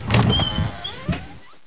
wood_door.wav